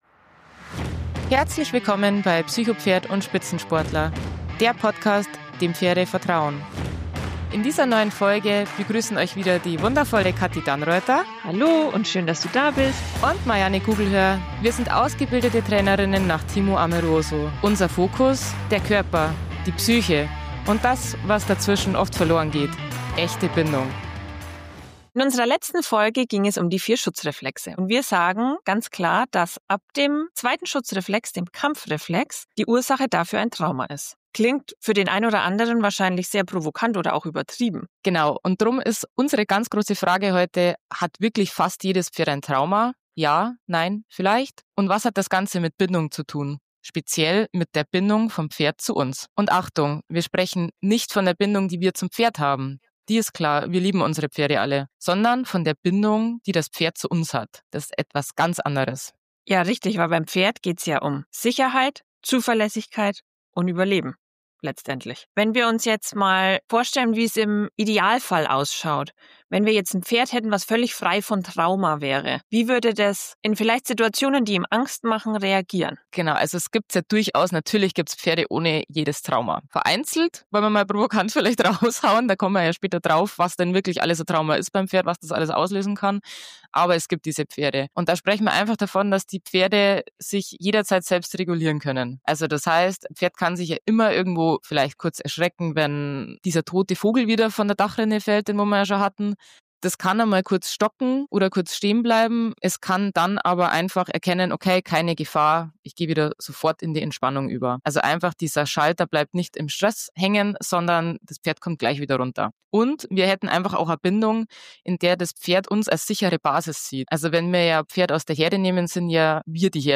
Wir beleuchten, wie Bindung beim Pferd neurobiologisch funktioniert, warum sie kein Trauma auflöst und wieso manche Verhaltensweisen nichts mit „Ungehorsam“, sondern mit alten Reflexmustern zu tun haben. Freut euch auf ein ehrliches, reflektiertes und tiefes Gespräch, das neue Perspektiven eröffnet und zeigt, wie wir Pferde wirklich verstehen können – jenseits von Konditionierung und Erziehen.